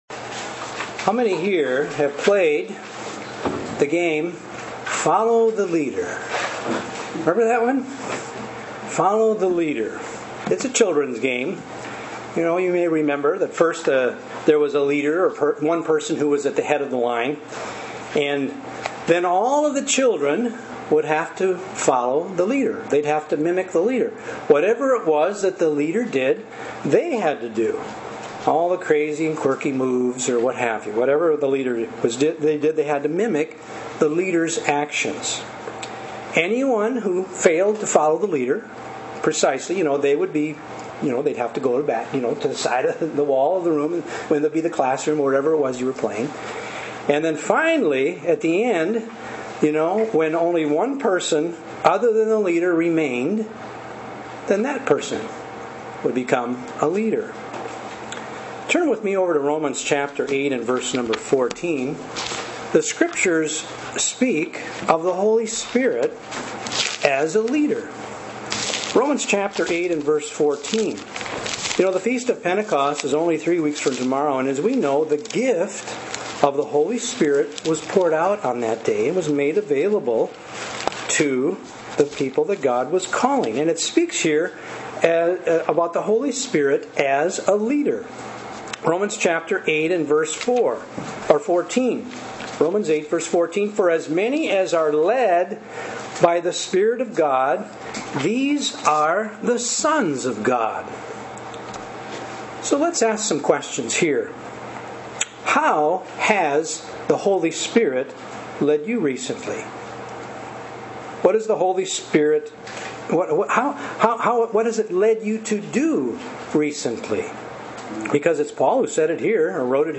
As Pentecost approaches this sermon will focus on the gift of God's Holy Spirit in how it can direct and lead us. Also, what the bible reveals about the Holy Spirit.